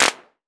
Vermona Clap 03.wav